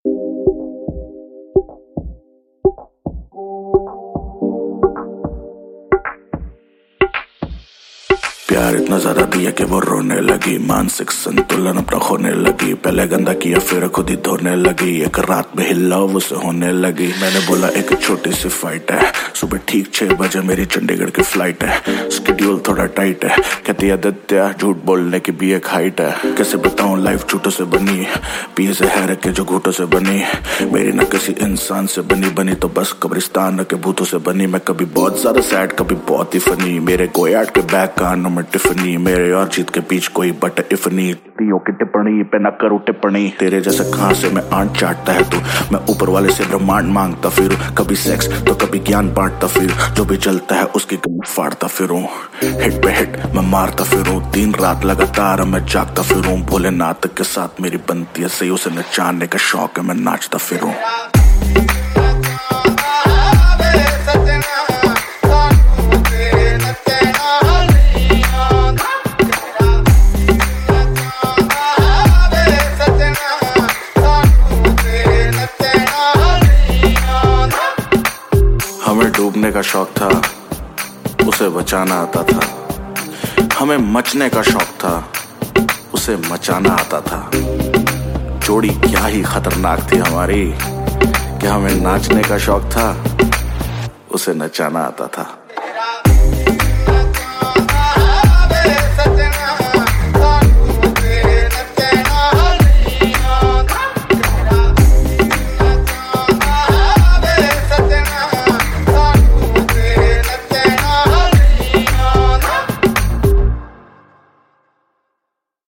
Hindi Pop Album Songs 2023